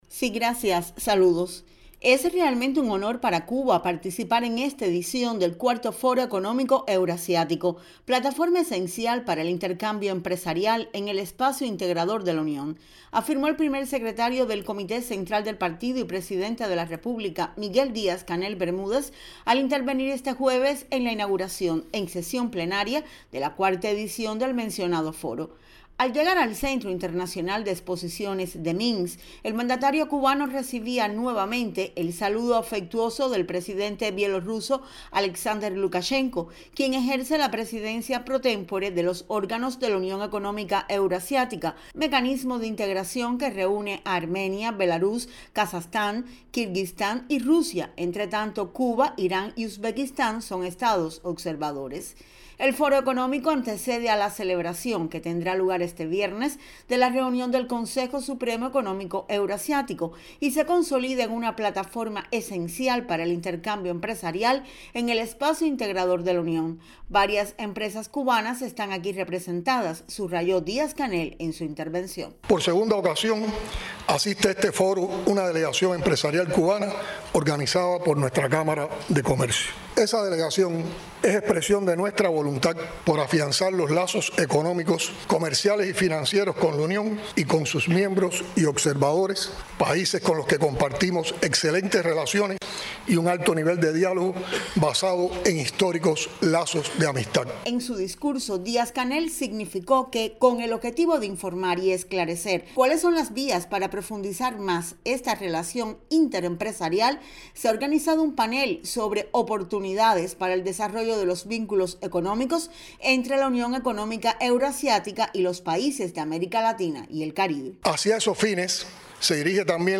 Primer Secretario del Comité entral del Partido Comunista y Presidente de la República, Miguel Díaz-Canel Bermúdez, propuso varias iniciativas a los Jefes de Estado y de Gobierno de las naciones que integran la UEEA, en el IV Foro Económico Euroasiático, que sesionó este jueves en la capital de Belarús.